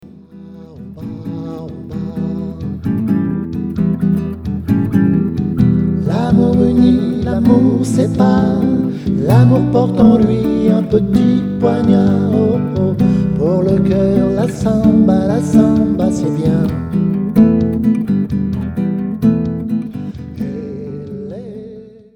version acoustique